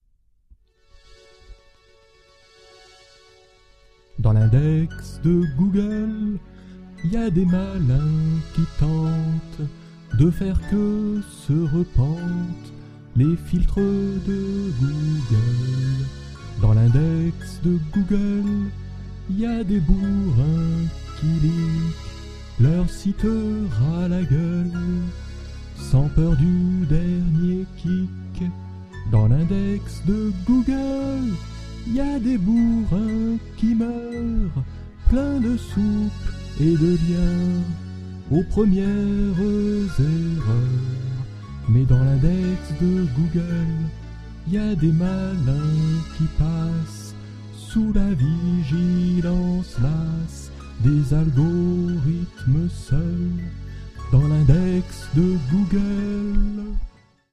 Parodie